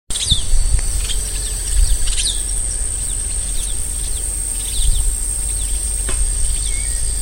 Dragão (Pseudoleistes virescens)
Nome em Inglês: Brown-and-yellow Marshbird
Localidade ou área protegida: Reserva Natural del Pilar
Condição: Selvagem
Certeza: Fotografado, Gravado Vocal